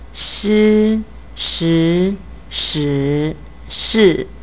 Nevertheless, here are some hints on how to differentiate the four tones in Chinese: As you can see, the little sign above the syllable indicates the level of the tone in which your voice should go.
fun-2~four-tones.wav